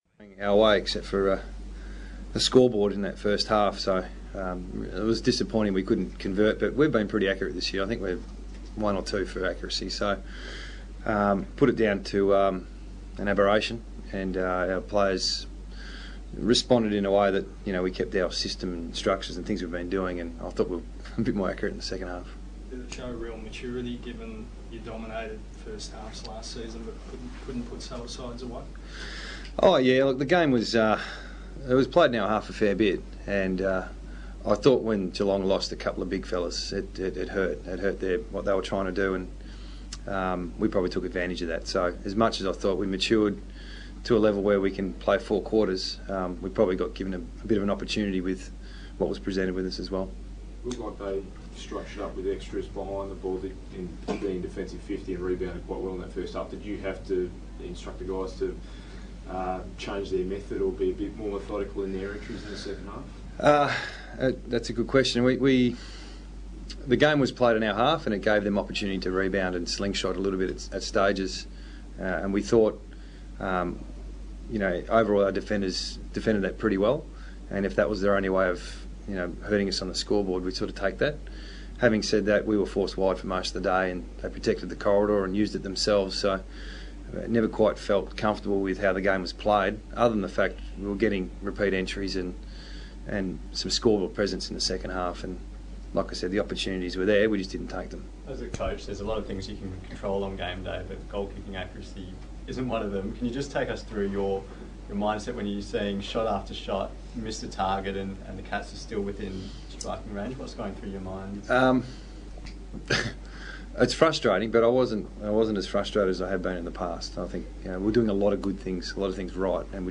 Adam Simpson Post Game Press Conference
Adam Simpson; West Coast Eagles; Coach; AFL